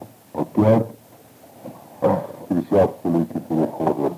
Мягкое цоканье (совпадение литературных аффрикат /ц/ и /ч’/ в мягком /ц’/)
/со-б’и-ра”-слэ соо-ц’и-н’а”-лы-сэ да-зы-ла-ты” по-ц’еее”-с’о-ны п’и:р/